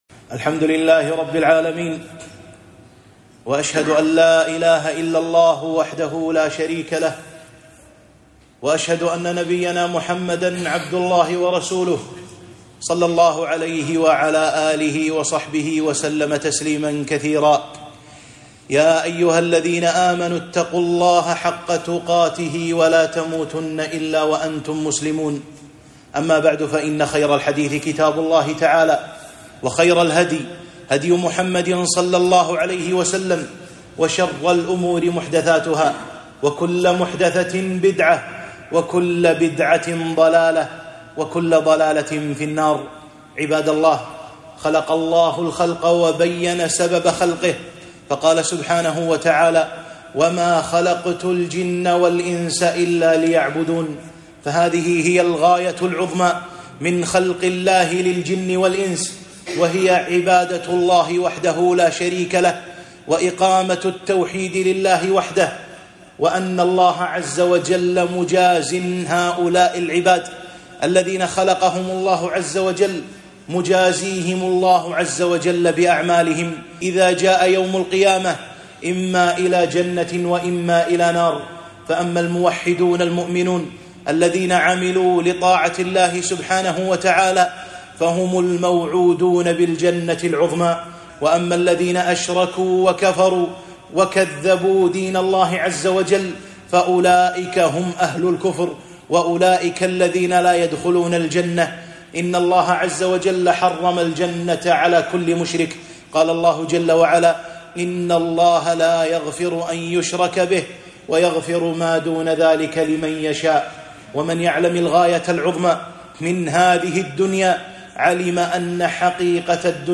خطبة - خطورة اللهث وراء المال والمنصب